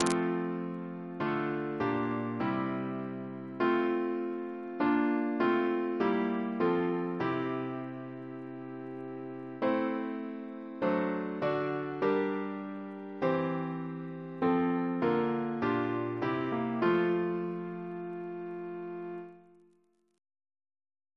Double chant in F Composer: James Turle (1802-1882), Organist of Westminster Abbey Reference psalters: ACB: 92; ACP: 57; CWP: 56; OCB: 22; PP/SNCB: 148; RSCM: 145